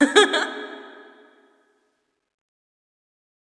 Metro Laughter 3.wav